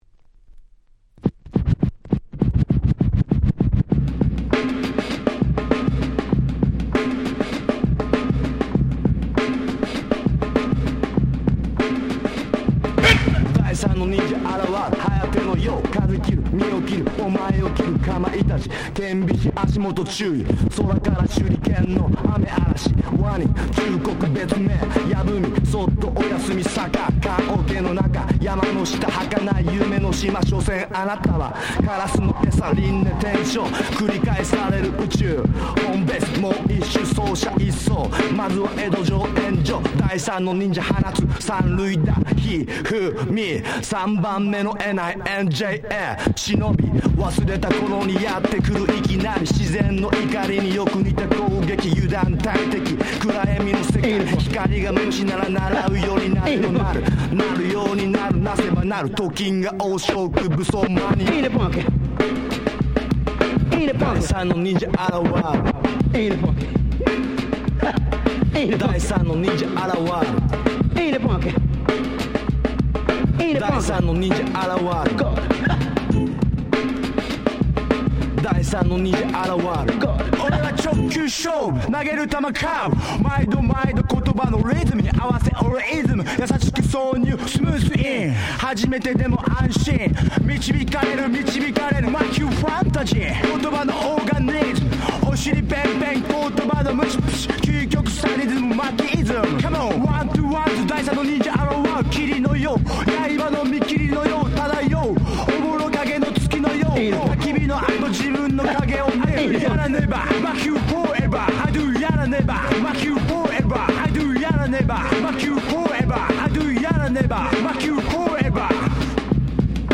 98' Japanese Hip Hop Classics !!
J-Rap